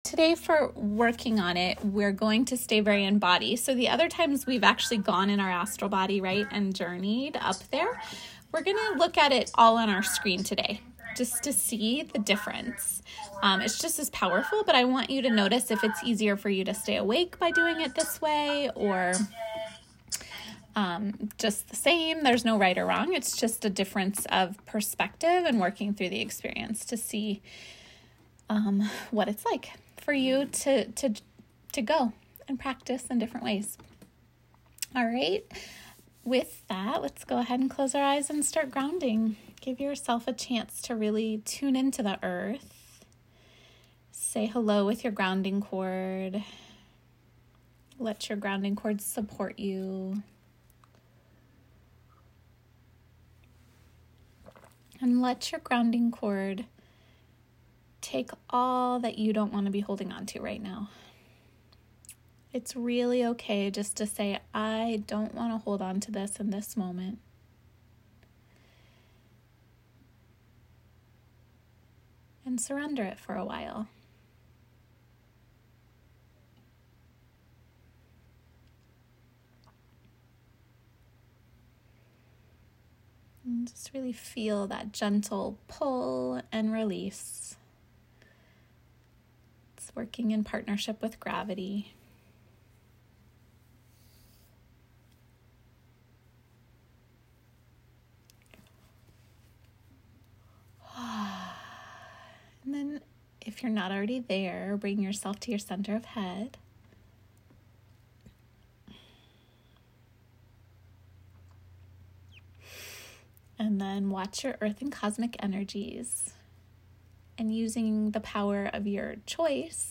Meditation: Akashic Records Journey
Expand upon your practice by taking a spiritual adventure, via guided meditation, to the Akashic Records to meet your Record Keeper (past life librarian/spirit guide) and get a sense of what the records are like Also learn a bit about how your soul develops it’s personality from lifetime to lifetime